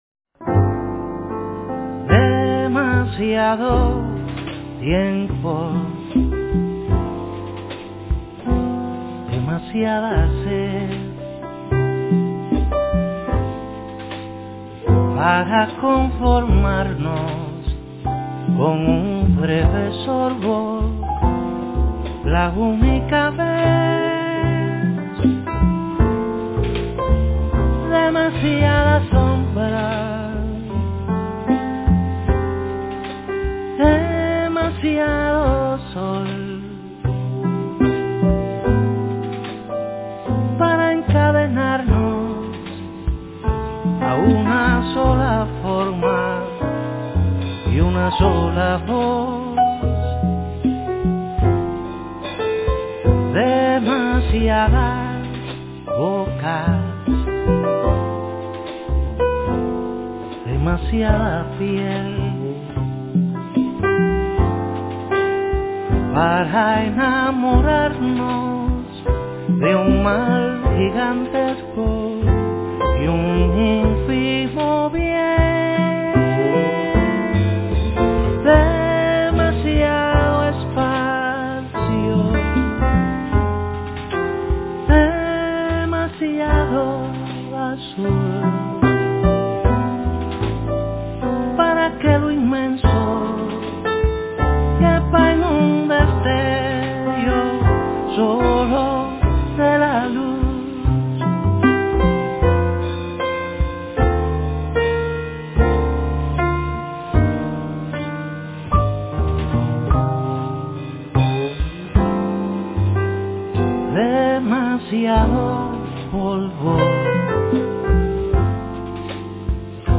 es un bolero clasico en su forma